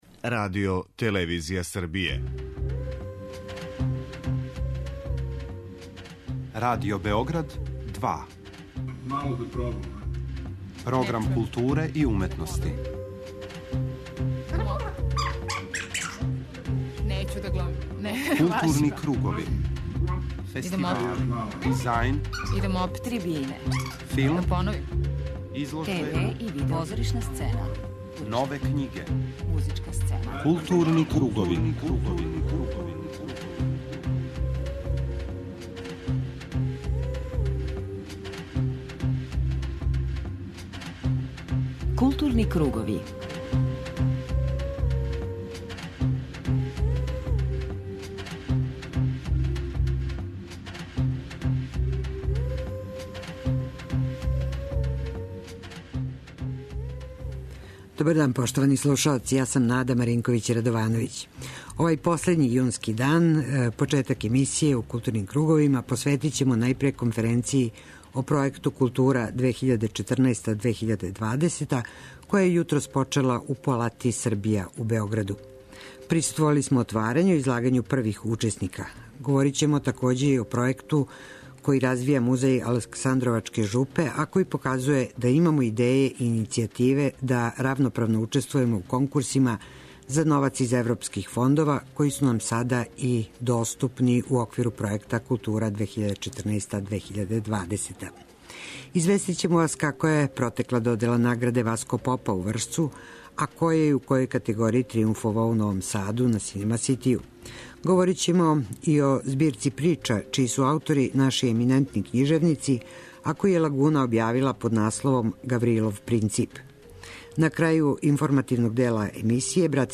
За данашњи блок Арс сонора између осталог смо издвојили концерт нашег изузетног клавирског ЛП дуа који слави своју 10-годишњицу, отварање традиционалног Фестивала оргуља са извођењем монументалне Пасије по Јовану, Јохана Себастијана Баха, као и концерте у оквиру аустријско-српске манифестације Заједно-Цузамен.
преузми : 54.12 MB Културни кругови Autor: Група аутора Централна културно-уметничка емисија Радио Београда 2.